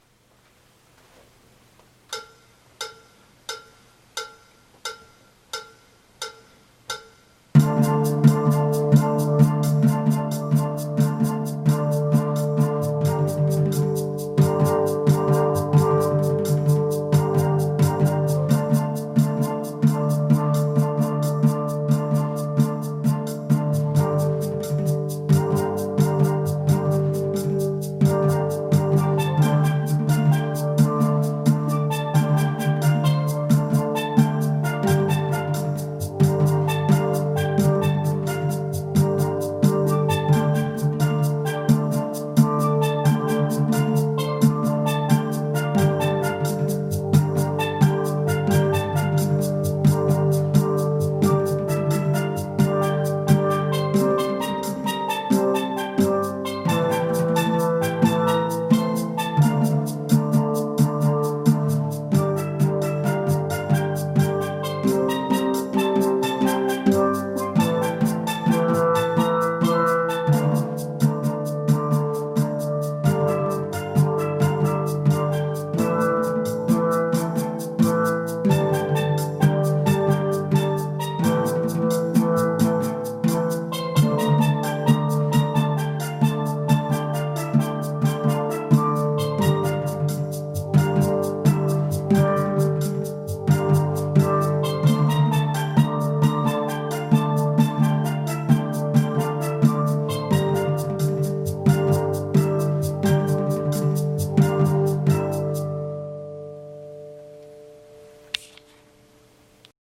Guitar Maloya Vid .mp3